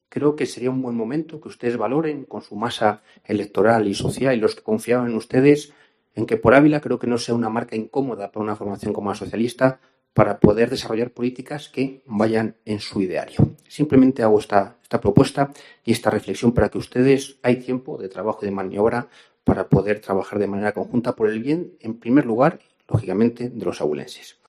José Ramón Budiño, portavoz Por Ávila. Pleno cuestión de confianza